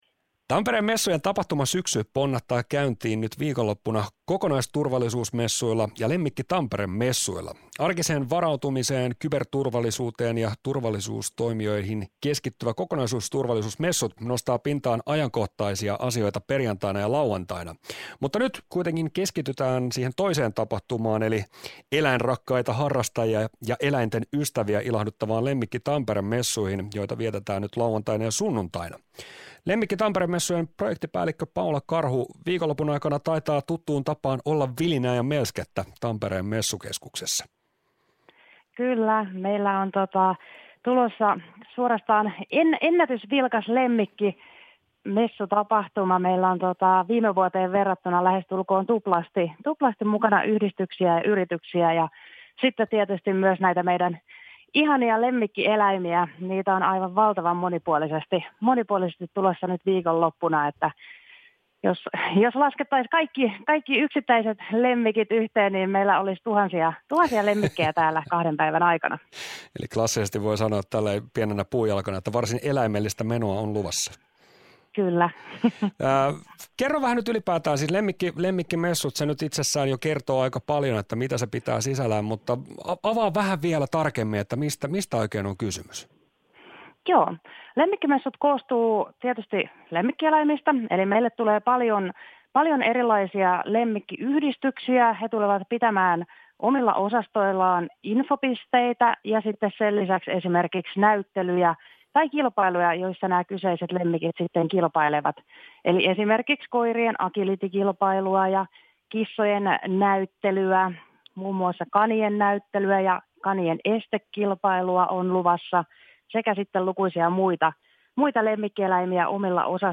Päivän haastattelu